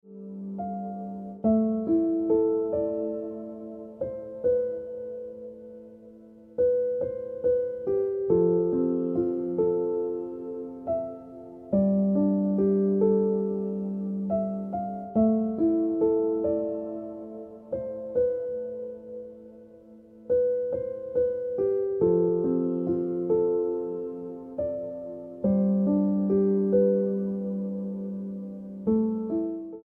Best Ringtones, Piano Music Ringtone